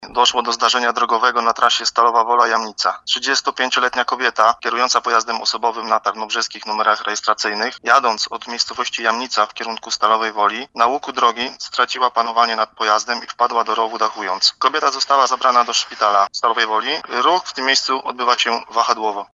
Doszło tam do dachowania samochodu mówi młodszy aspirant